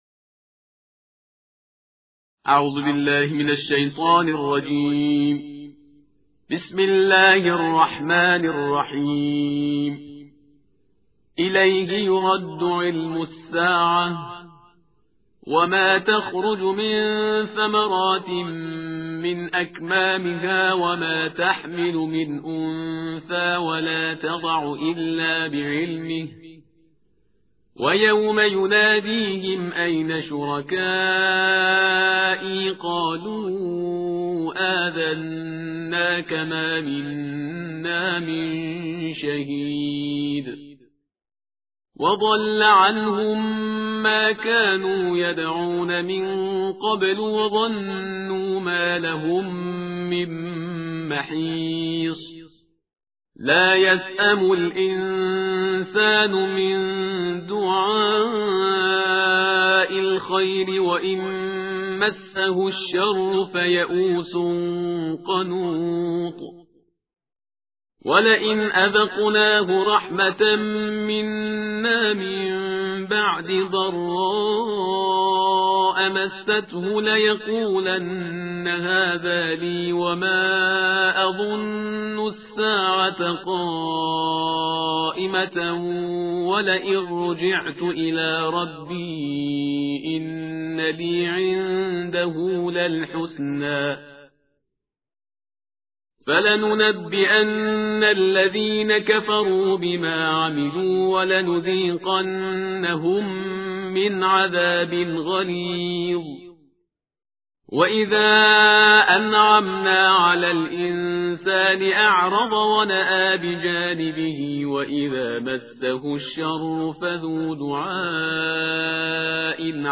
ترتیل جزءبیست و پنج قرآن کریم